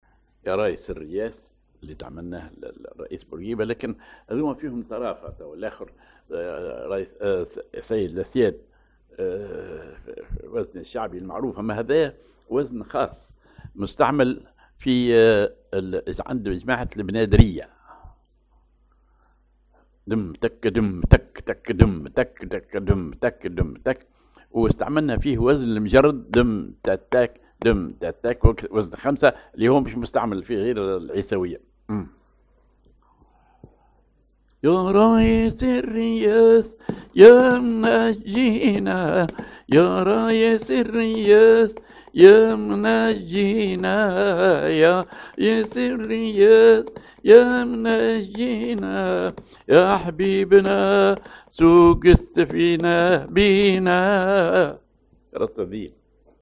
Maqam ar راست الذيل
Rhythm ar استفتوح
genre نشيد